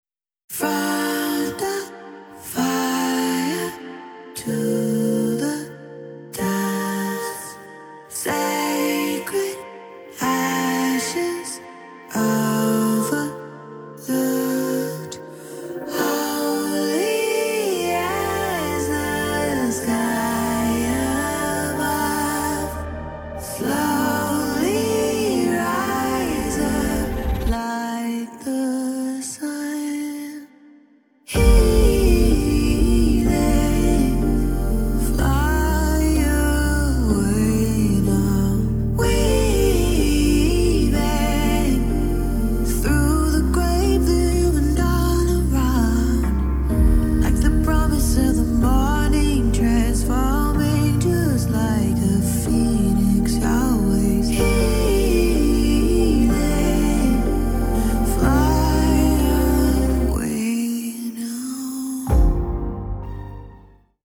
Indie Pop